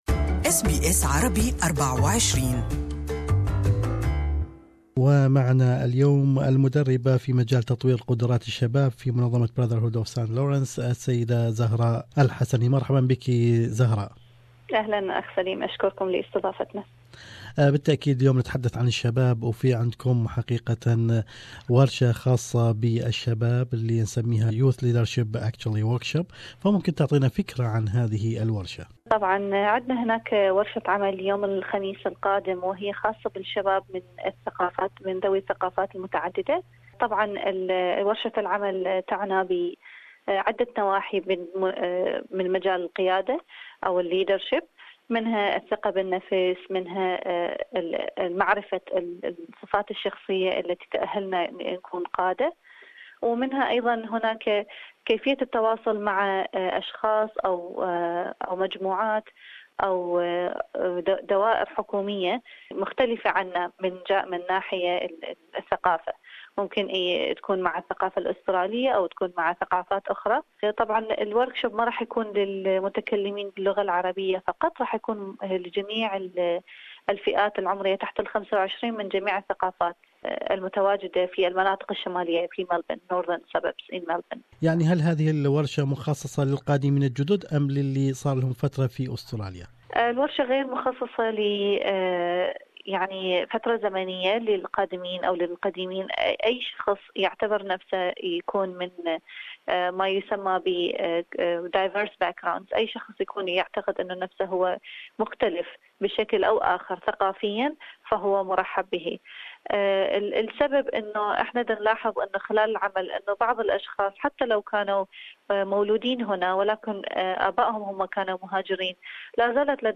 وللتعرف اكثر على تفاصيل هذه الورشة واهميتها كان لنا هذا اللقاء